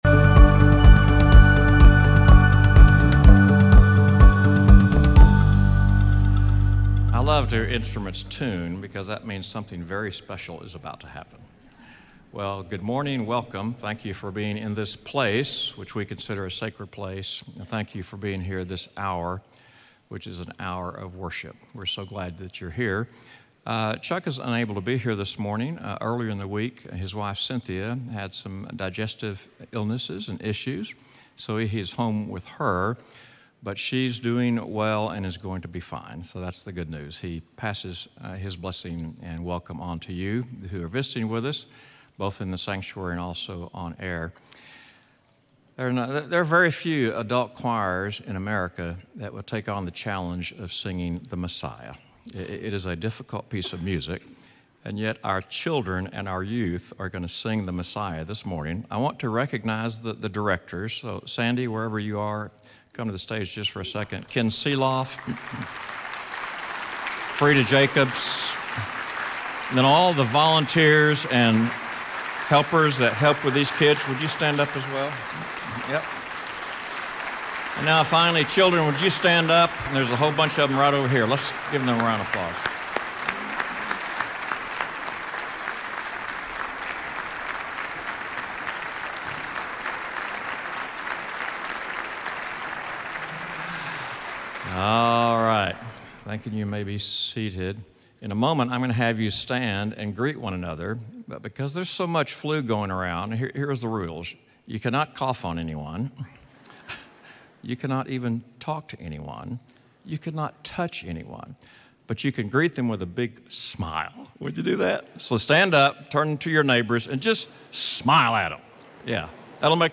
Children’s & Youth Choirs Christmas Concert
Enjoy a Christmas classic as our Children’s and Youth Choirs present Handel’s Messiah for Young Voices. In this special concert, songs and Scriptures will guide us through the story of Jesus…